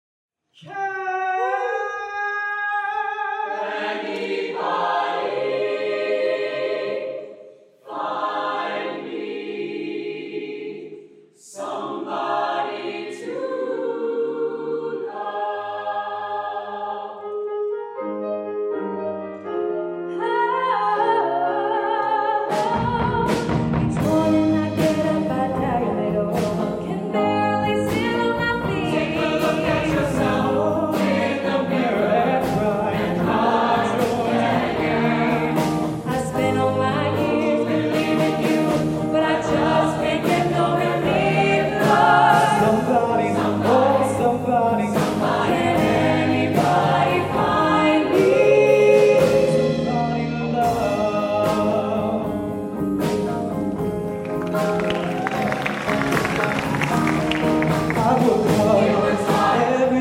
four curricular choirs and two a cappella ensembles
Sing With Heart: Spring Concert, 2019
With: Vocal Ensemble